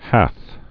(hăth)